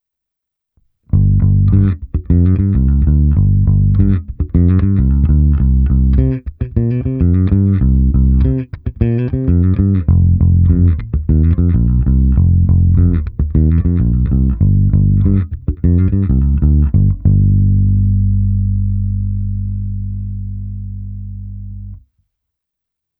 V řetězci dále byl aktivní preamp Darkglass Harmonic Booster, kompresor TC Ellectronic SpectraComp a preamp Darkglass Microtubes X Ultra se zapnutou simulací aparátu.
Cívky 1 a 2 - zvuk ala '60 Jazz Bass - basy +30, středy +10, výšky +30